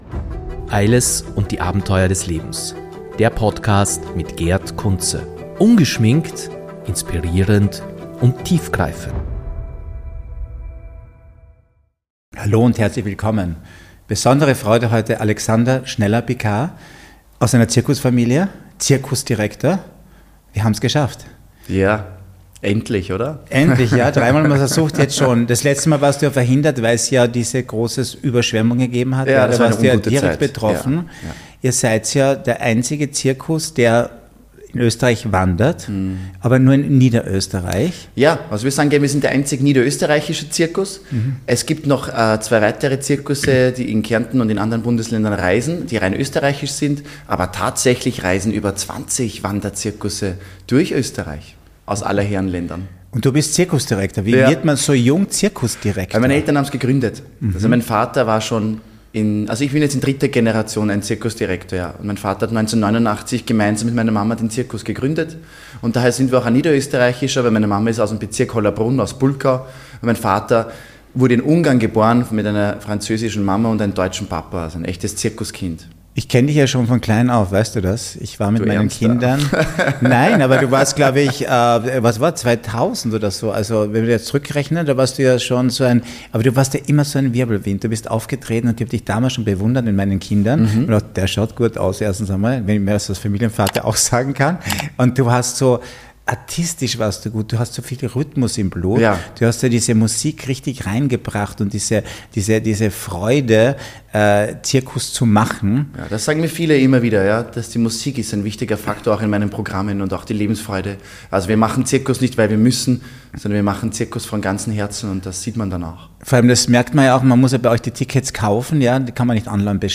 Seine positive Lebenseinstellung und die Liebe zum Zirkus sind dabei unverkennbar und machen dieses Gespräch zu einem echten Highlight. Erfahrt mehr über den Alltag im Zirkus, die Bedeutung von Musik und Lebensfreude in seinen Shows und wie es ist, als offen homosexueller Zirkusdirektor in Österreich zu arbeiten.